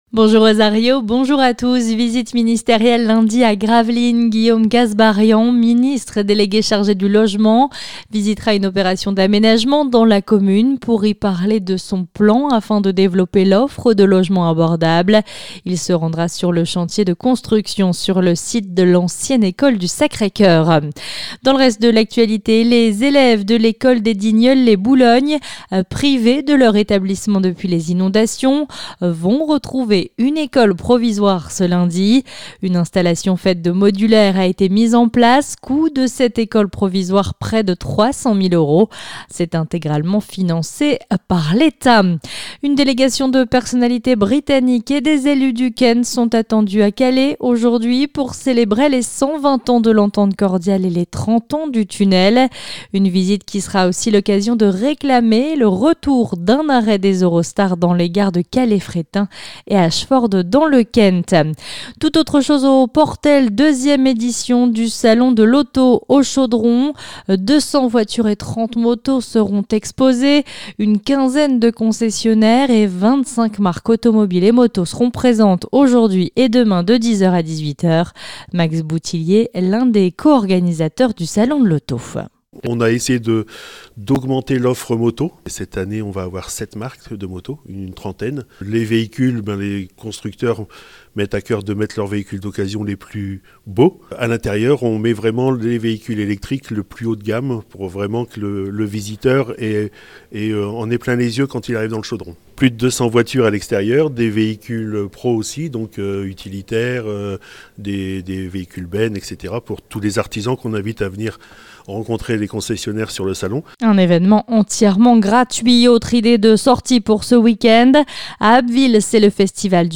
Le journal du samedi 4 mai